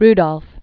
(rdŏlf), Wilma Glodean 1940-1994.